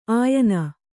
♪ āyana